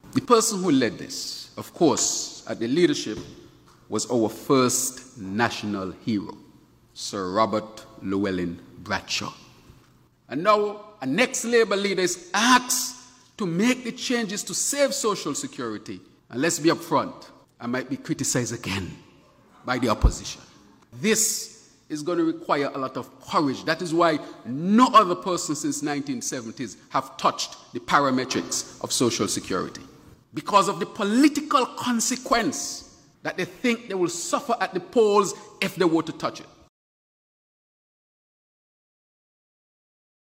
“Refining Today, For A Sustainable Tomorrow” was the theme for a Stakeholder Reform Conference, which was hosted by the St. Christopher and Nevis Social Security Board hosted on April 21st at the St. Kitts Marriott Resort.
PM Dr. Terrance Drew.